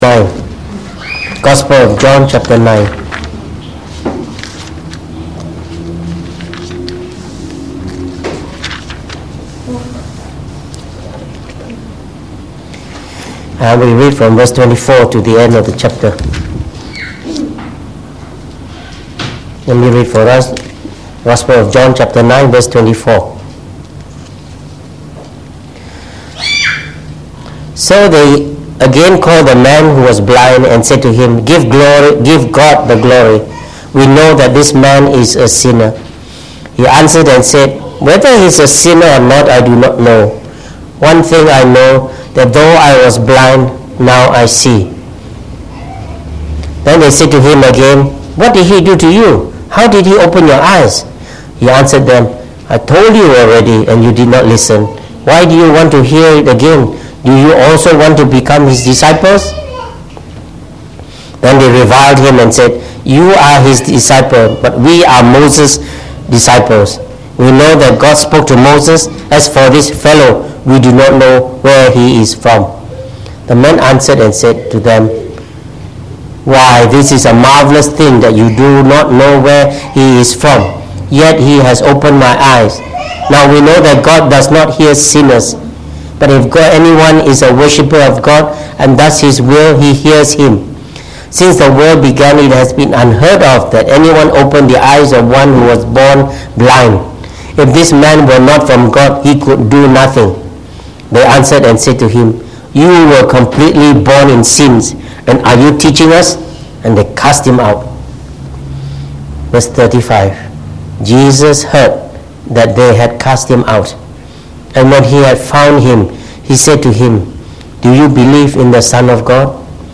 Preached on the 13th January 2019. From our series on the Gospel of John delivered in the Evening Service